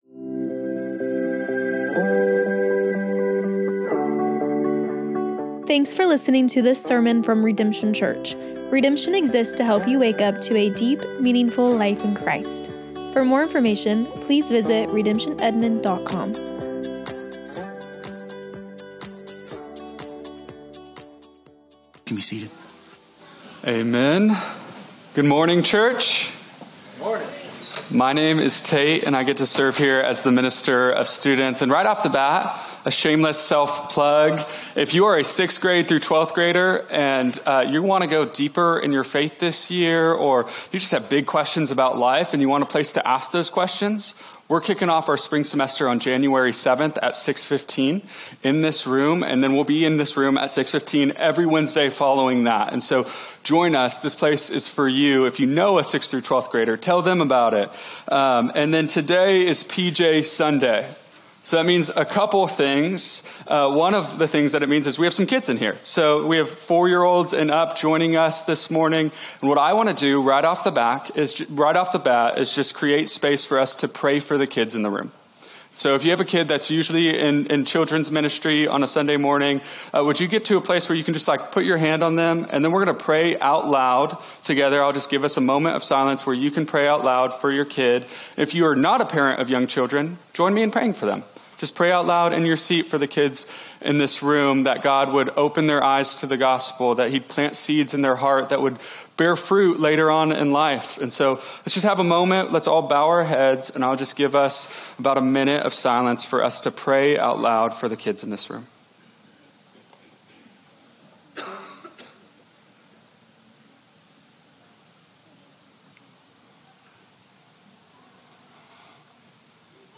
Redemption Church - Sermons